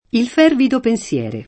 pensiero [penSL$ro] s. m. («il pensare») — poet. pensiere [penSL$re]: il fervido pensiere [
il f$rvido penSL$re] (Parini) — es. di tronc. in poesia (anche nel pl.): Quanti dolci pensier, quanto disio [kU#nti d1l©i penSL$r, kU#nto di@&o] (Dante); Un pensier mesto della madre cara [um penSLHr m$Sto della m#dre k#ra] (Giusti) — in poeti antichi, anche pensero [penS$ro]: Al celato amoroso mio pensero [